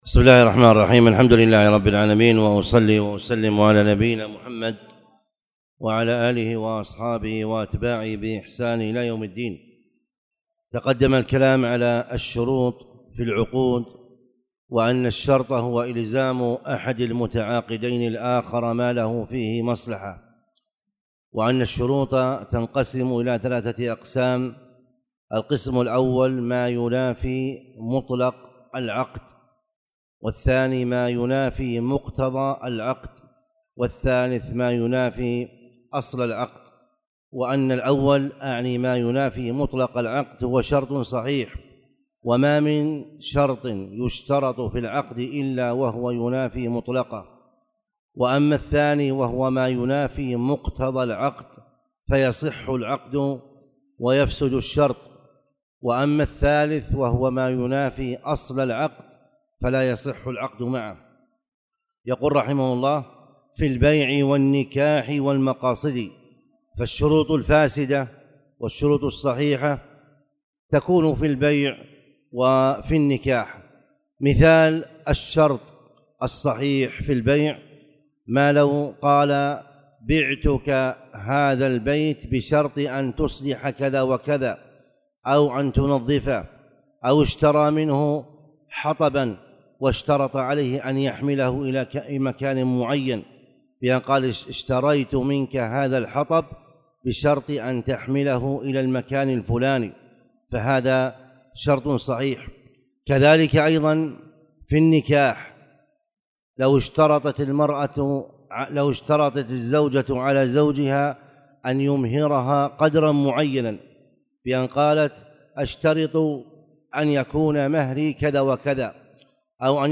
تاريخ النشر ١٧ صفر ١٤٣٨ هـ المكان: المسجد الحرام الشيخ